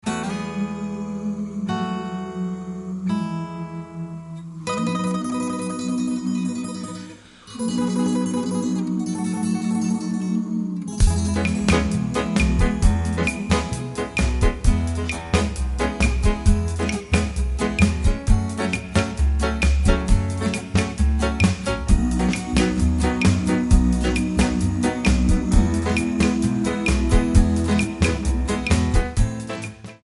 Backing track files: 1960s (842)